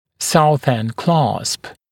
[‘sauθend klɑːsp][‘саусэнд кла:сп]Southend-кламмер